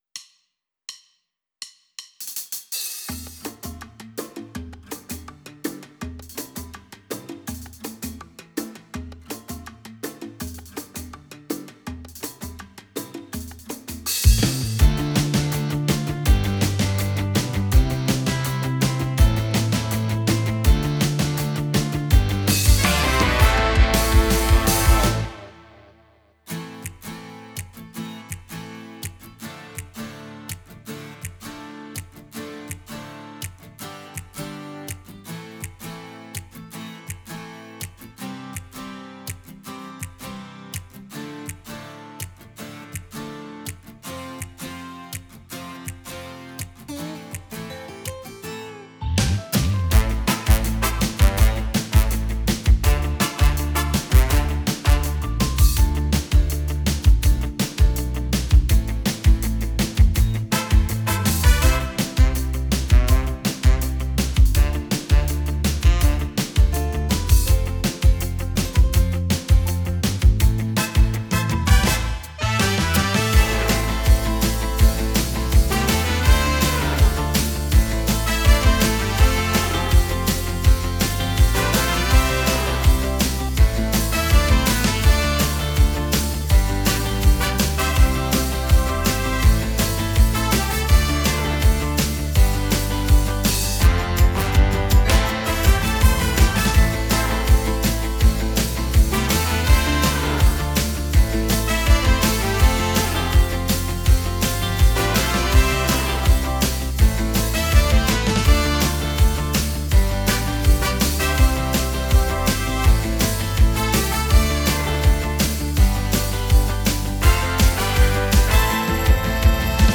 Original Key- A minor - 164 bpm